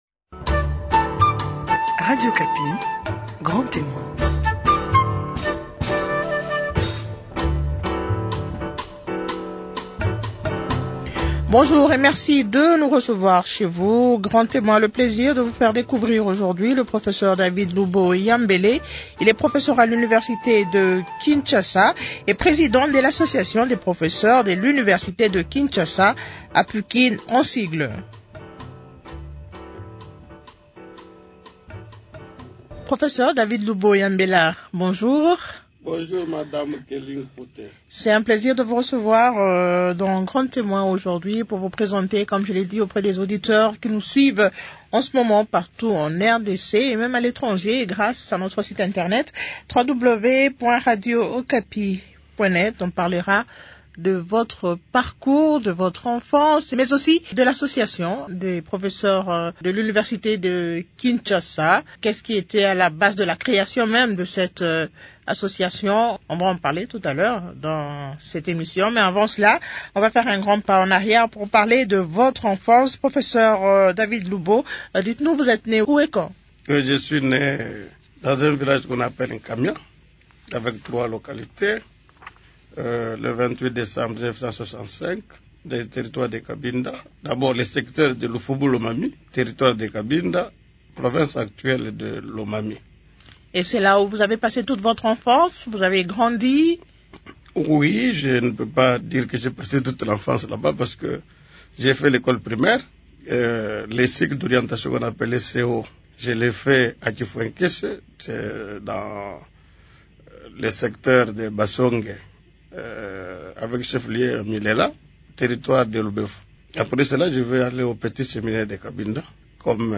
Découvrez aussi dans cet entretien, son bref passage en politique .